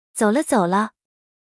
traffic_sign_green.wav